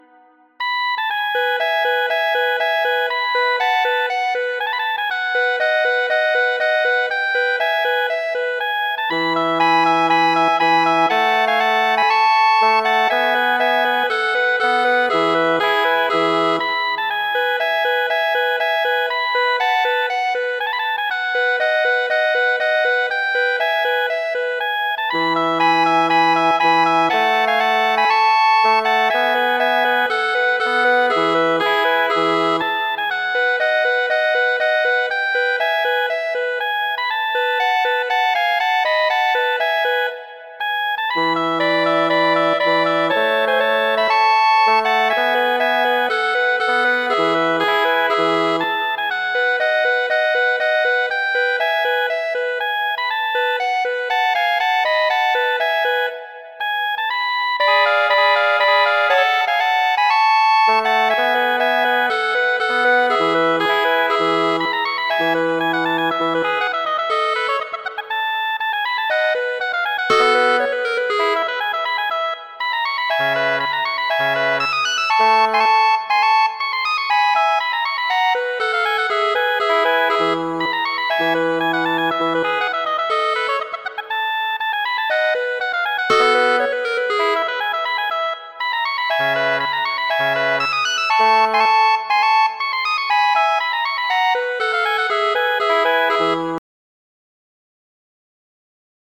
Variaĵoj pri la temo de l' aŭtomatoj de la Magia Fluto, opero de W. A. Mozart..